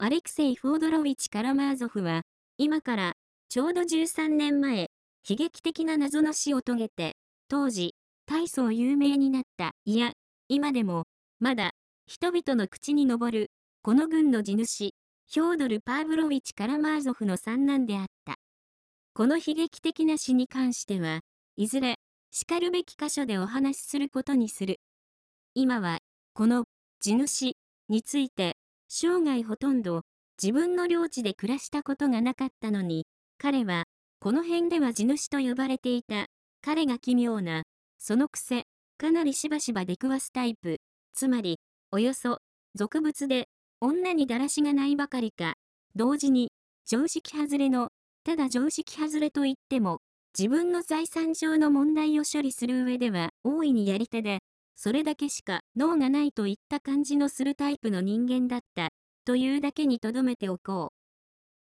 電子書籍や、音声朗読機能を使ってみるのも、良さそうなので、試しに作ってみました。
男性の声